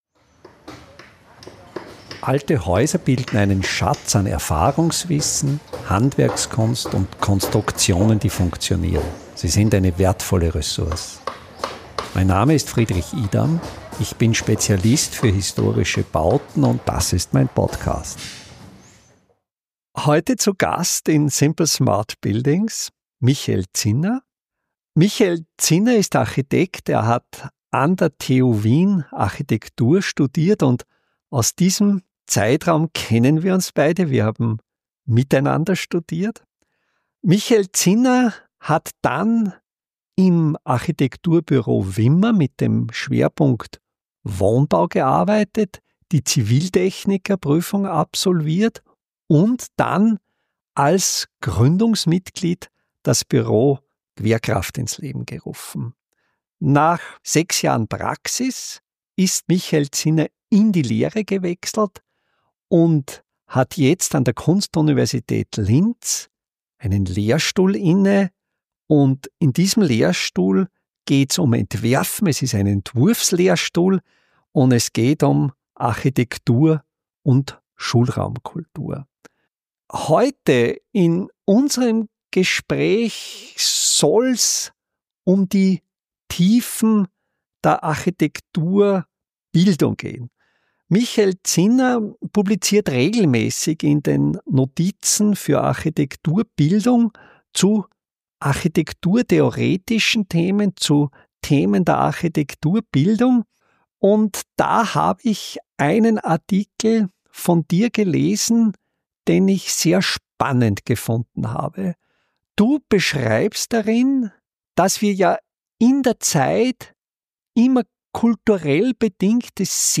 Gespräch über die Zukunft des Bauens ~ Simple Smart Buildings Podcast